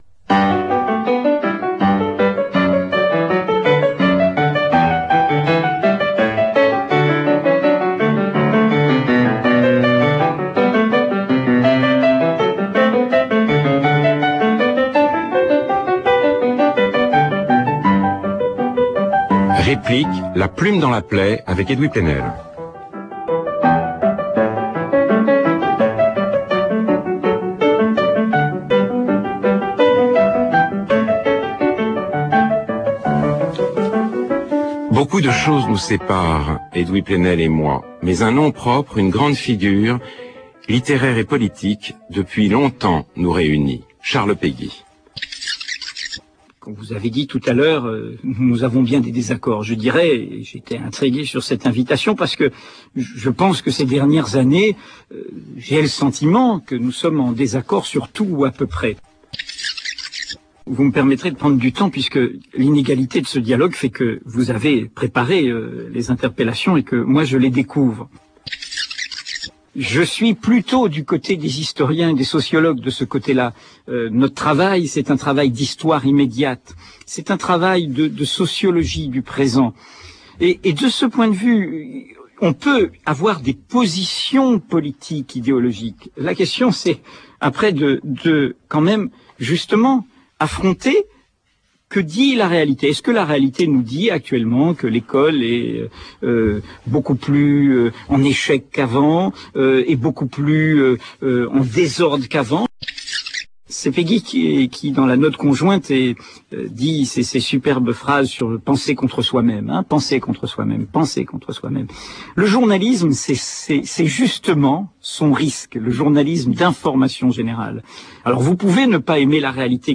Afin de donner une idée de la qualité d’une discussion de haut niveau sur France Culture, observons le duel entre un intervieweur frustré de ne pas être reconnu par l’interviewé et un interviewé persuadé de sa propre importance.